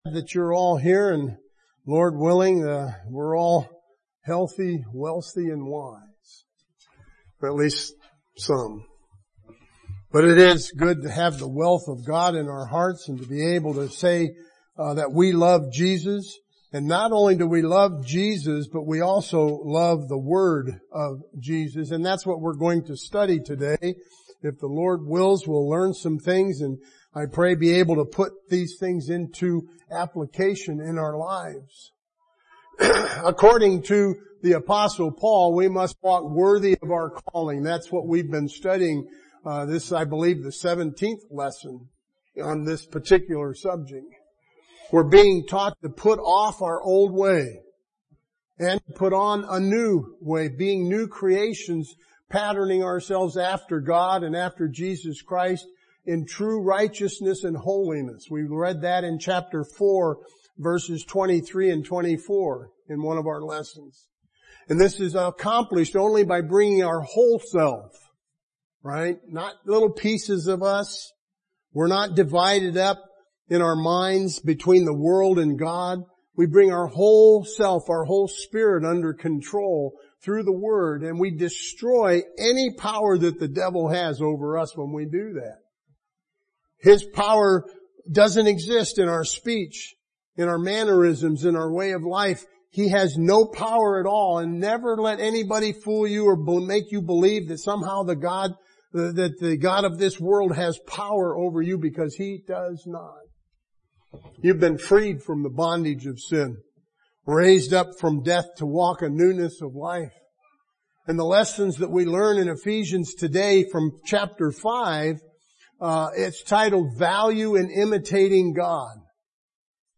This lesson is from Ephesians chapter 5 – and is titled “Value in Imitating God”